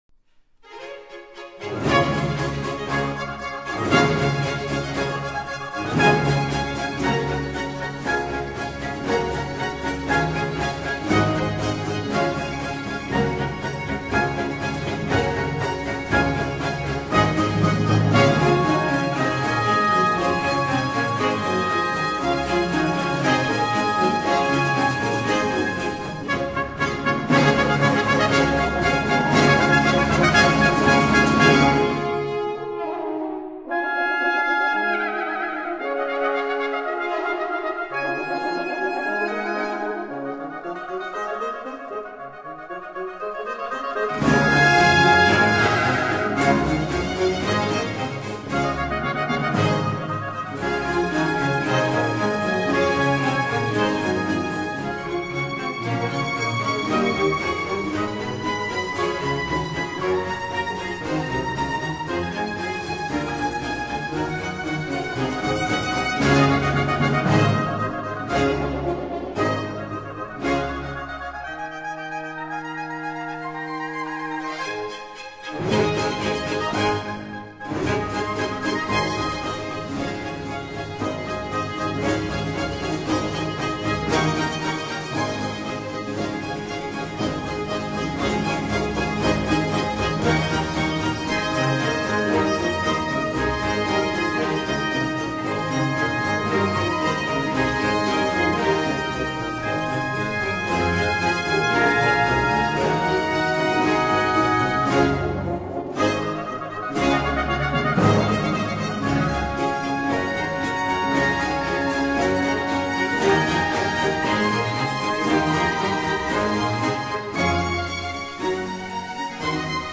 Allegro-Minuet.Contradanza